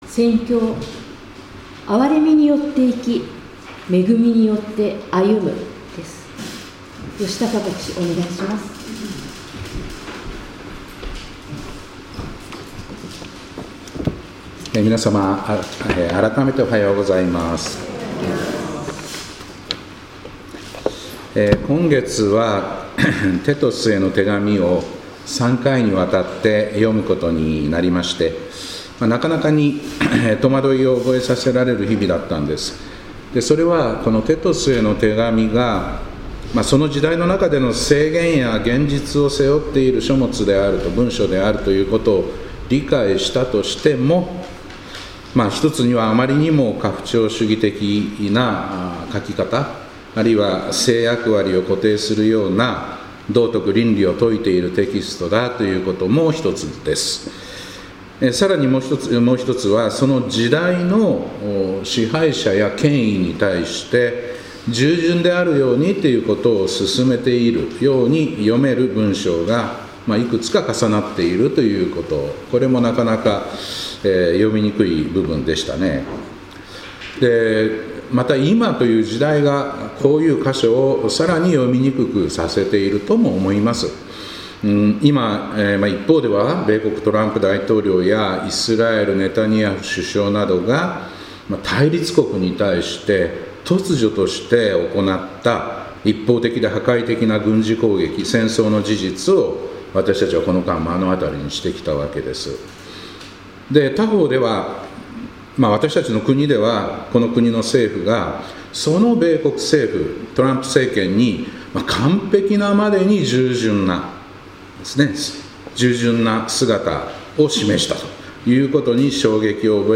2026年4月26日礼拝「憐れみによって生き、恵みによって歩む」